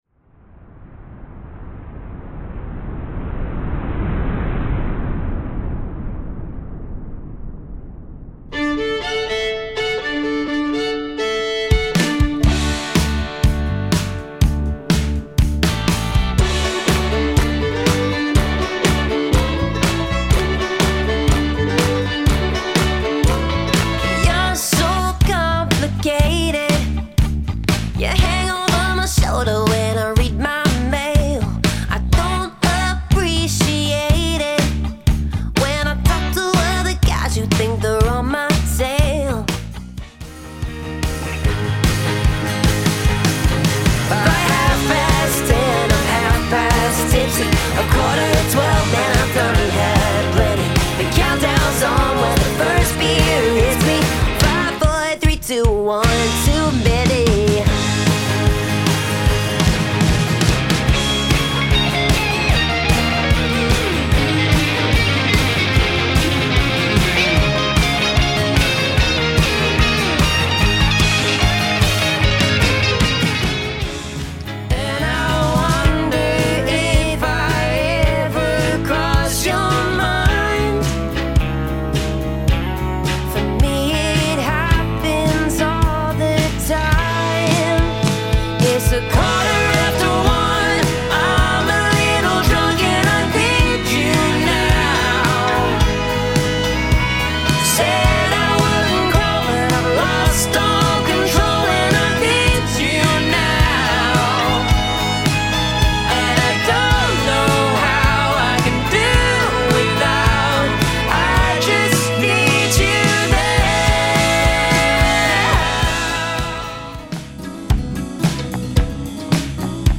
• Fresh country takes on well-known chart songs
• Strong vocal harmonies
Vocals, Guitar, Drums, Fiddle, Banjo, Backing Tracks